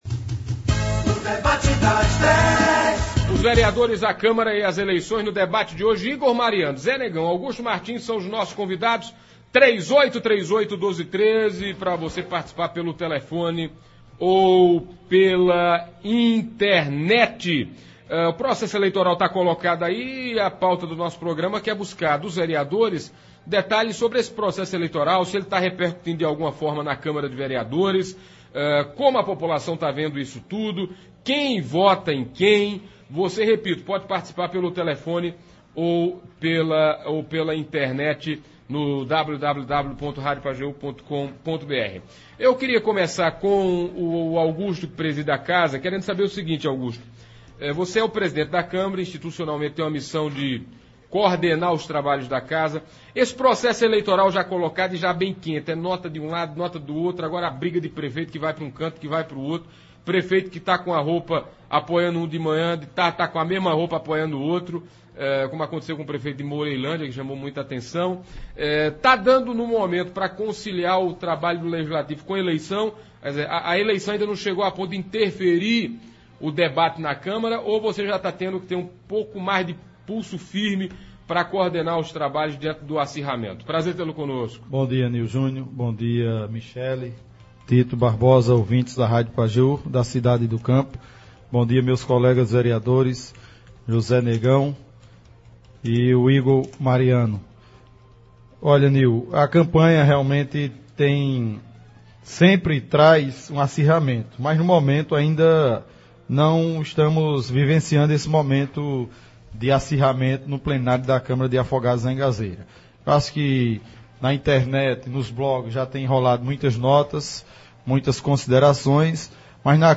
Hoje (14) nos estúdios da Pajeú o presidente da Câmara de Vereadores Augusto Martins (PTB) e os vereadores Igor Sá Mariano (PSDB), e Zé Negão (PROS), falaram da postura dos vereadores no processo eleitoral, quem vota em quem, como estão definindo os apoios e como andam os debates na Câmara.